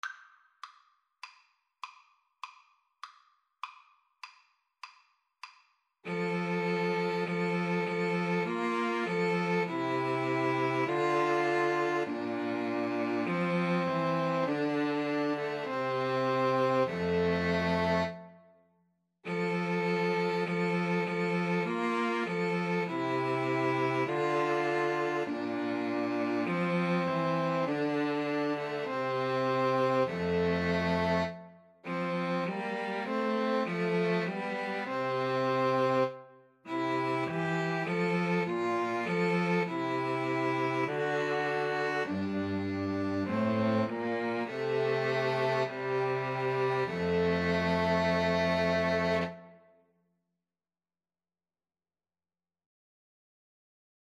Violin 1Violin 2Cello
4/4 (View more 4/4 Music)
F major (Sounding Pitch) (View more F major Music for 2-Violins-Cello )